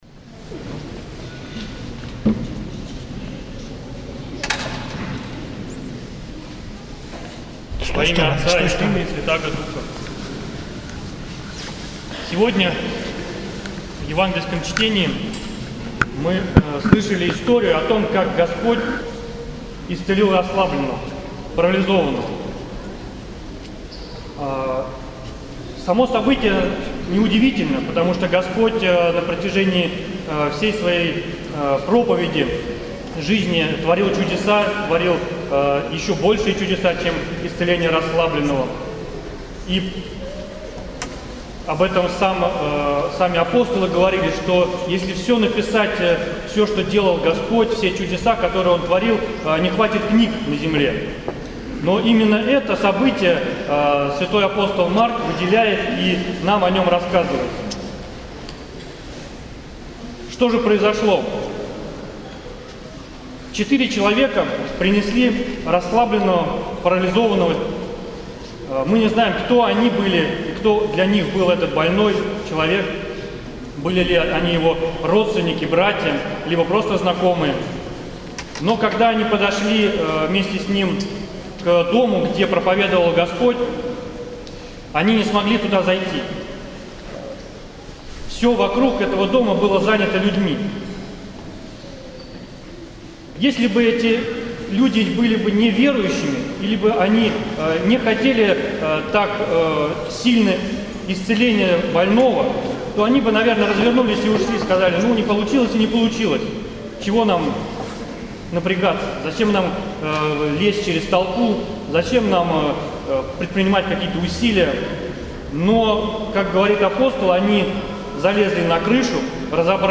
поздняя Литургия